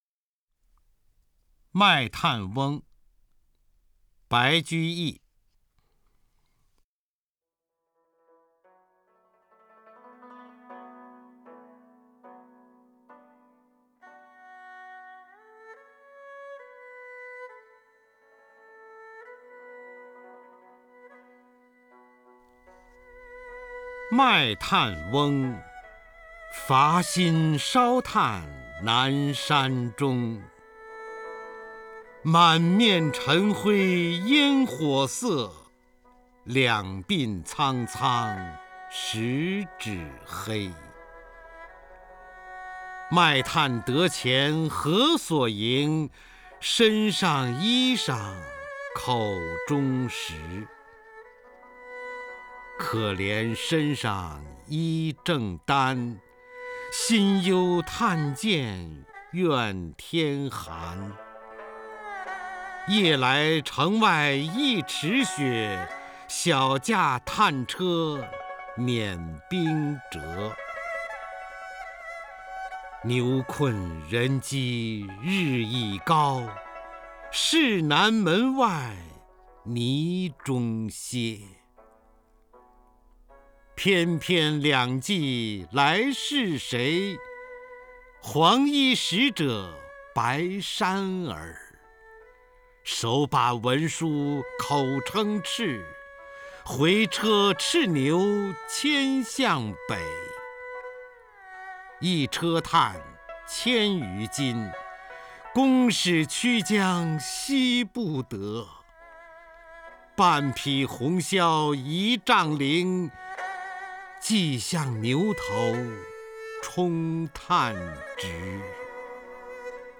首页 视听 名家朗诵欣赏 方明
方明朗诵：《卖炭翁》(（唐）白居易)　/ （唐）白居易